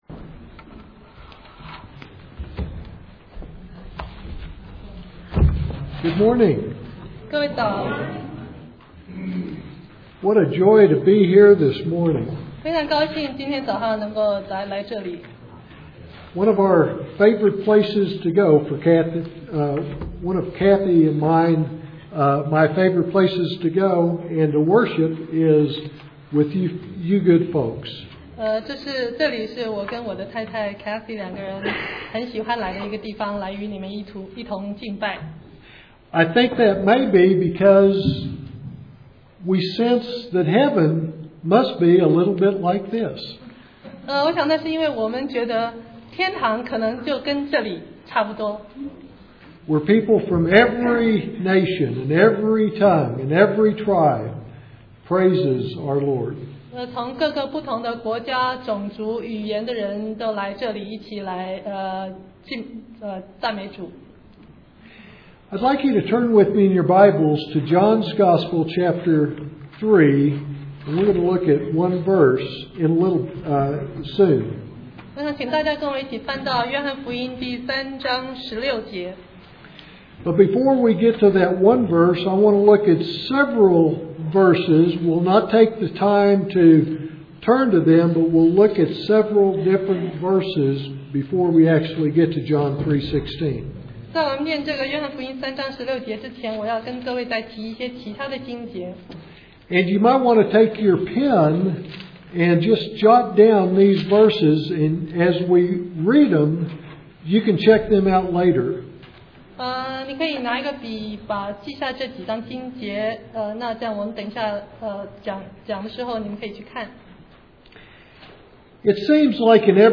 Sermon 2008-05-25 What and Why Give?